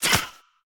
File:Sfx creature babypenguin hold unequip above 01.ogg - Subnautica Wiki